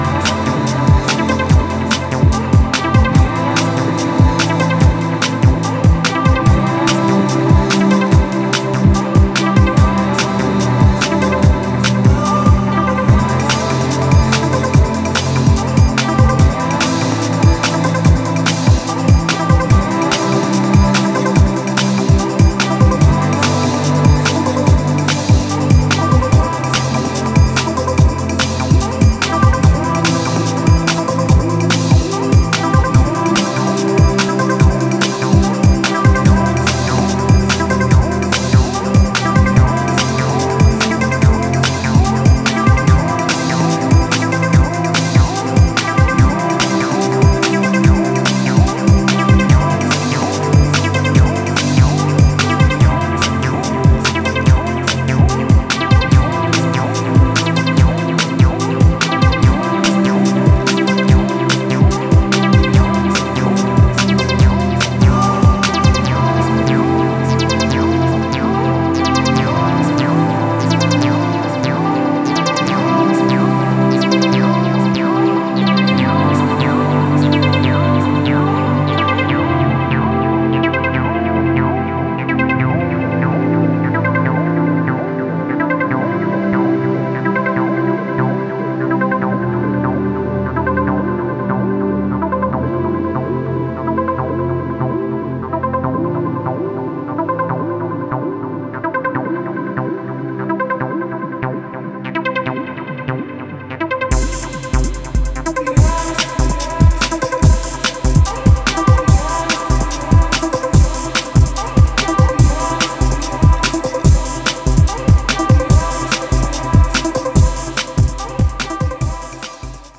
オーケストラルなハイテンポブレイクビーツ